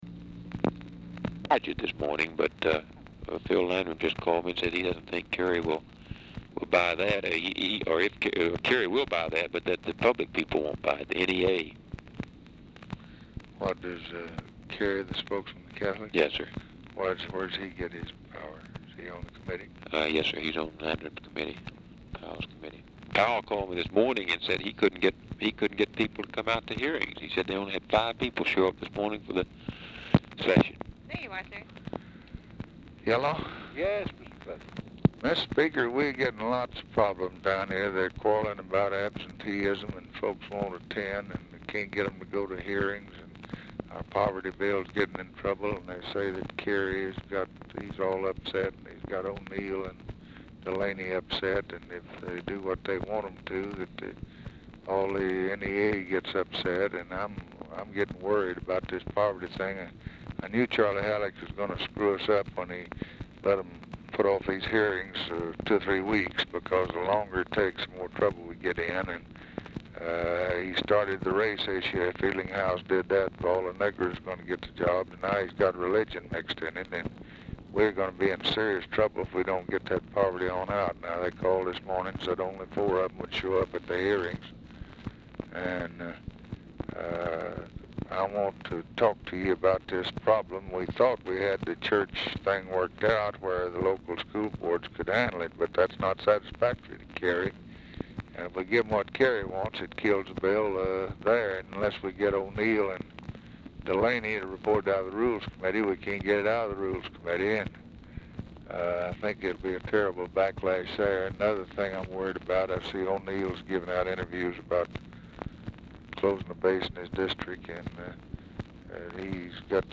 SOME SKIPPING; MOYERS APPARENTLY ON ANOTHER PHONE, SPEAKS WITH LBJ BEFORE MCCORMACK DOES, ALSO SPEAKS WITH MCCORMACK
Format Dictation belt
Location Of Speaker 1 Oval Office or unknown location
Specific Item Type Telephone conversation